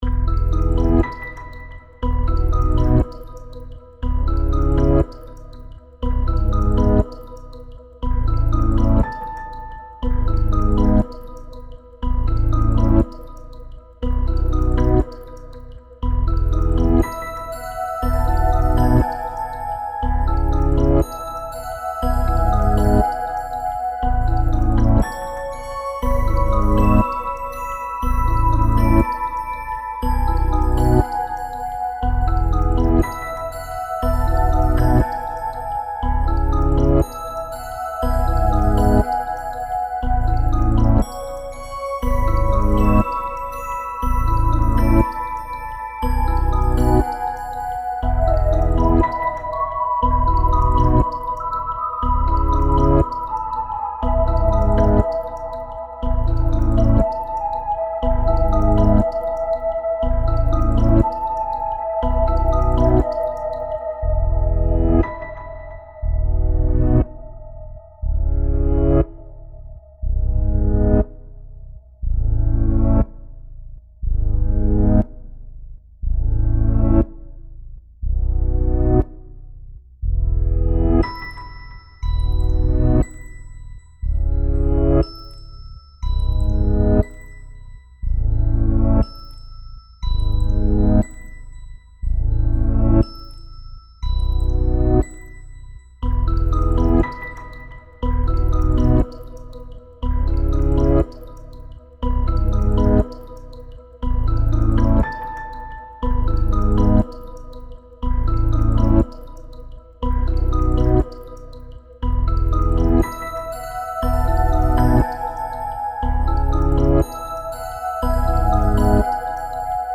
ふわふわとした寂しげだけど優しい曲
アンビエント 3:12